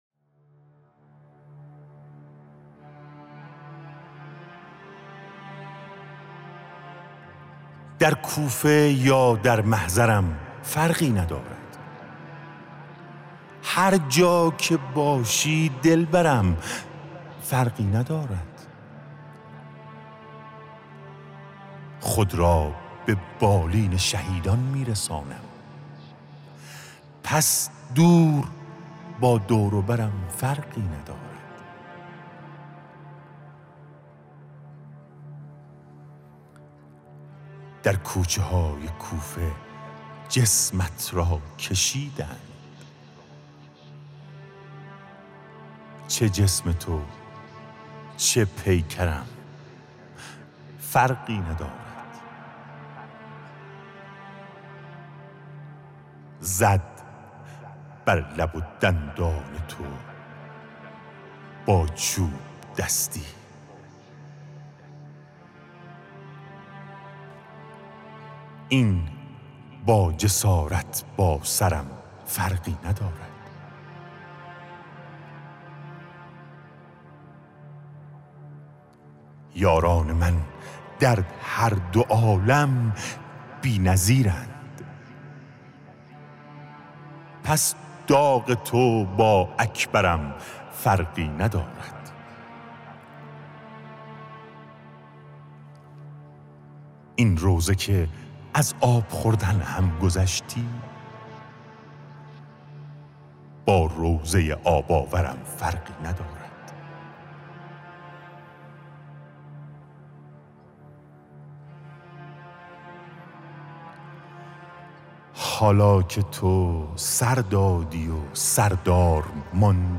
حسینیه مجازی